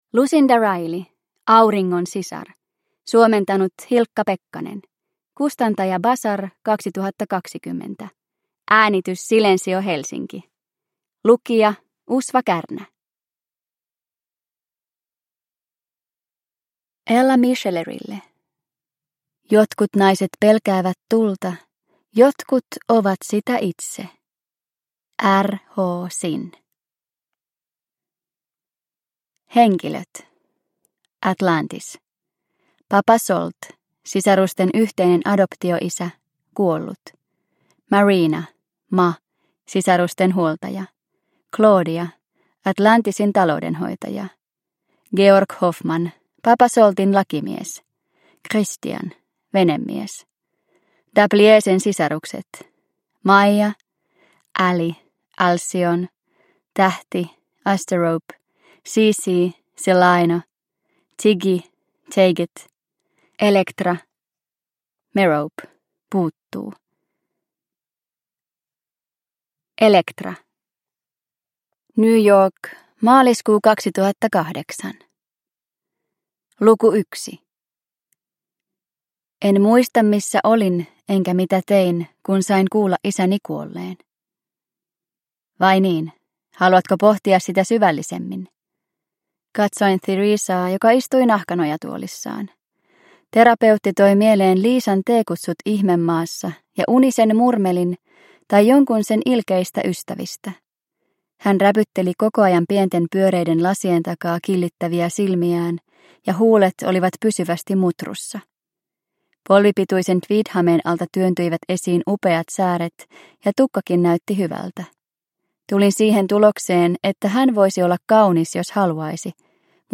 Auringon sisar – Ljudbok – Laddas ner